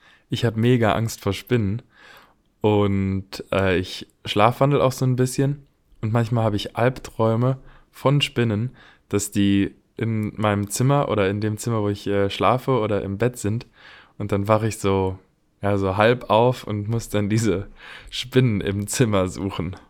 Deutscher Sprecher, Helle Stimme, Junge Stimme, Mittel alte Stimme, Schauspieler, Sänger, Werbesprecher, Off-Sprecher, Dokumentation, Geschichte, Buch
Sprechprobe: Sonstiges (Muttersprache):